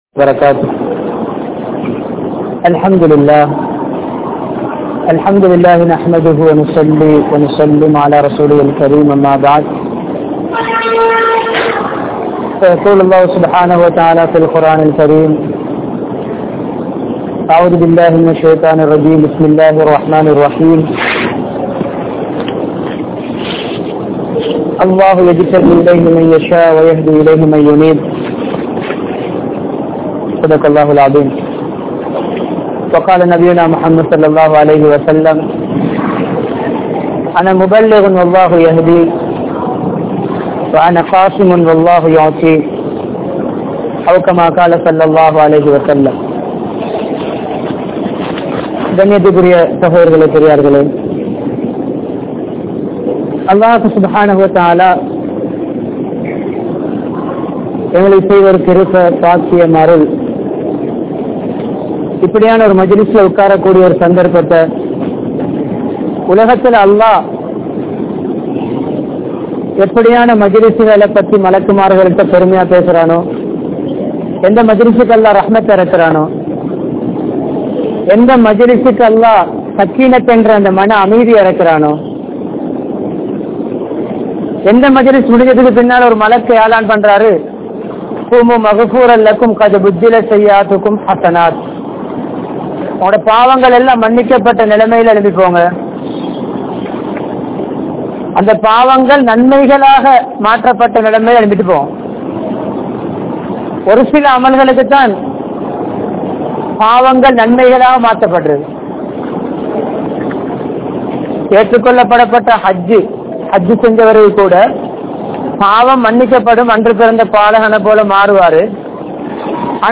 Allah`vidam Anthasthai Perungal (அல்லாஹ்விடம் அந்தஸ்தை பெறுங்கள்) | Audio Bayans | All Ceylon Muslim Youth Community | Addalaichenai
Kandauda Jumua Masjidh